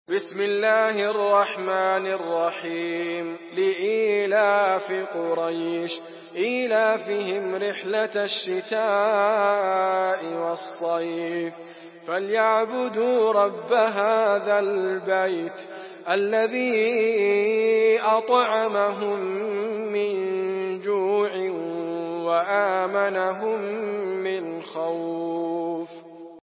Surah Quraish MP3 by Idriss Abkar in Hafs An Asim narration.
Murattal Hafs An Asim